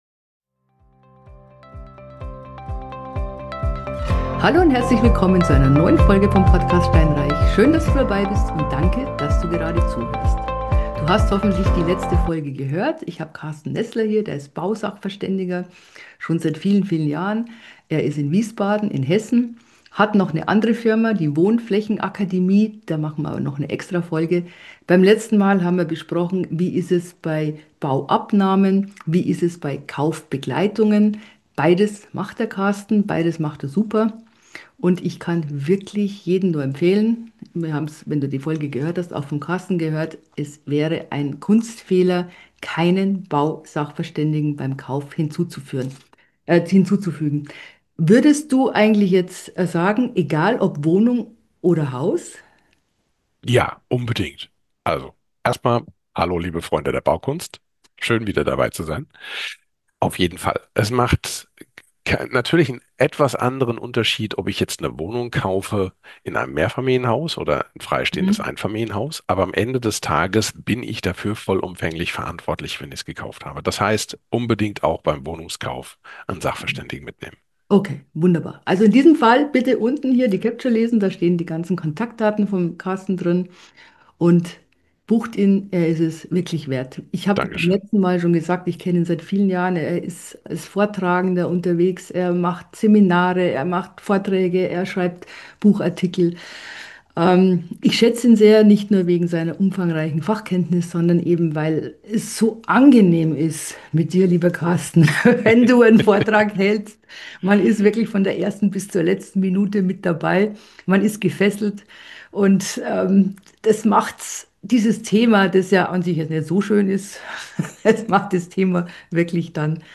Die häufigsten Bauschäden interview